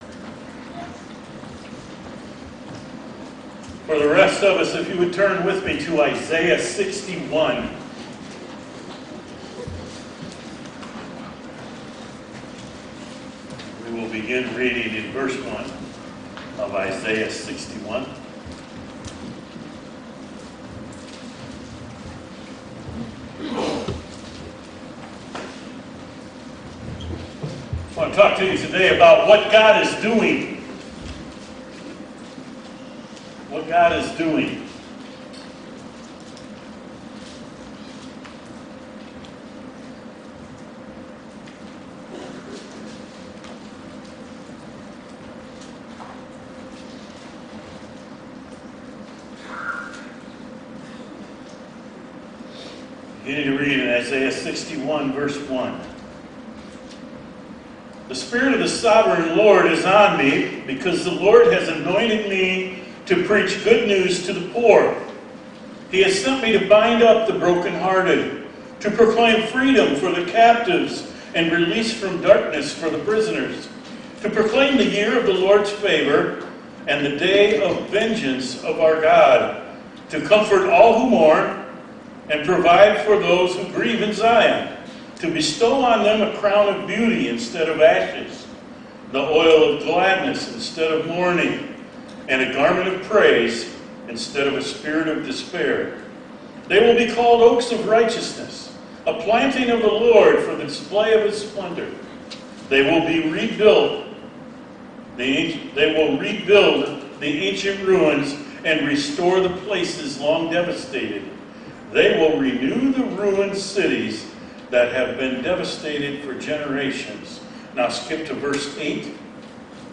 Audio Only Version